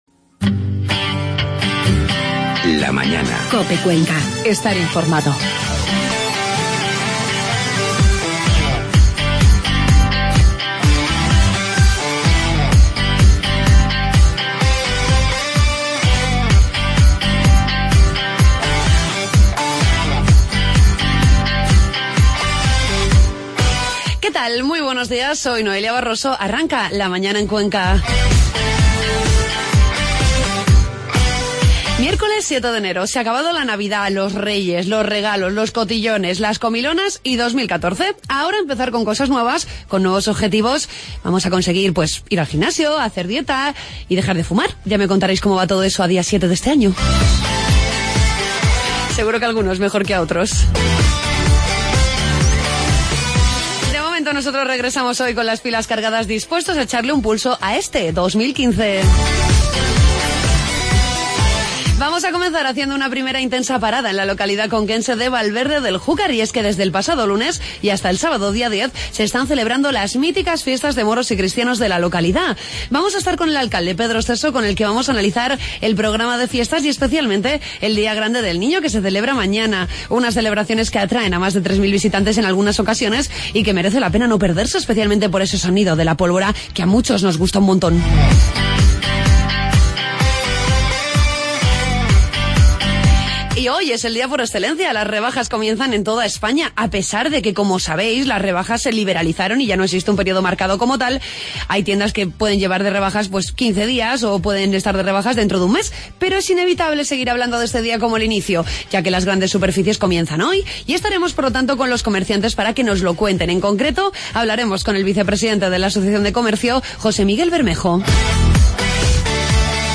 Comenzamos el año conociendo en profundidad las celebraciones de los Moros y Cristianos de Valverde del Júcar con el alcalde del municipio, Pedro Esteso. Terminamos esta primera parte escuchando el tema Bell Shot.